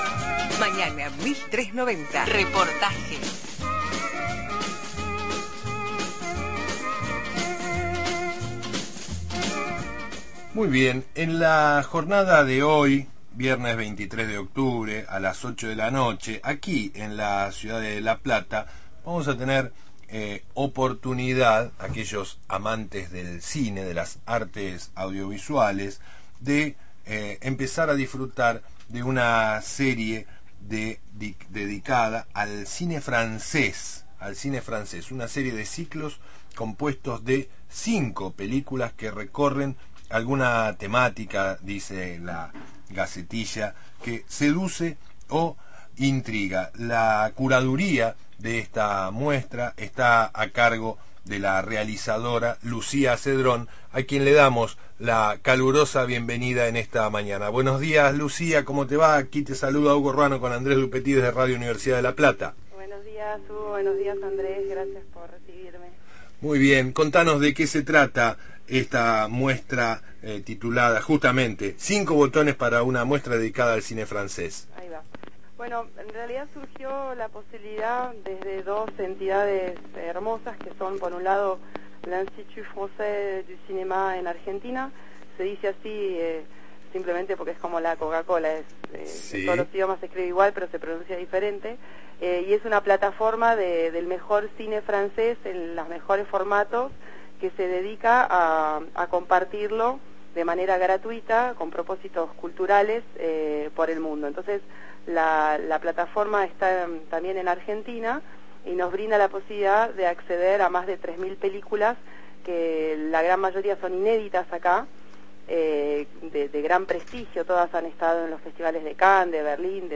cineasta